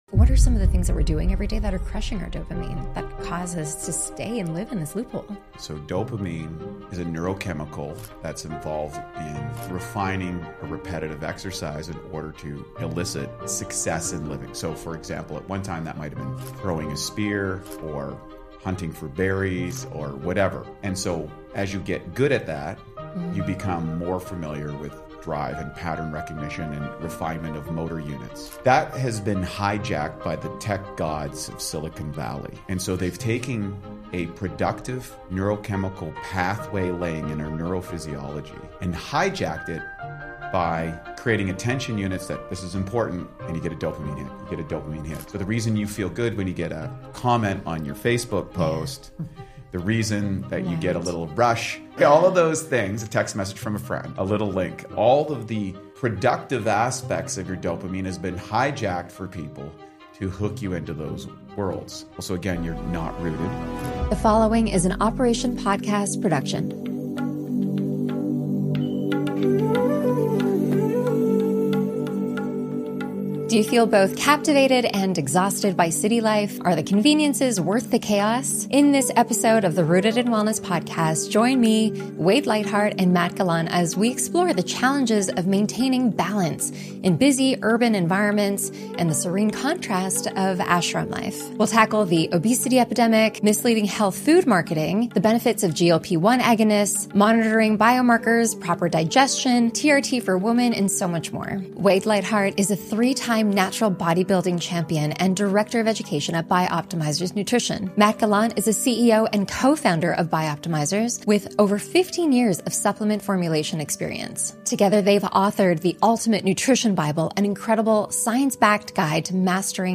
We talk about how ancient wisdom like Qigong and Chinese medicine can transform the way we approach stress, digestion, relationships, and even weight loss. We also discuss how simple, mindful practices can help us shift out of "fight or flight" mode and into a state of flow—where our bodies naturally heal and thrive. From rethinking your morning routine to creating rituals that connect you with nature, this conversation is packed with valuable insights.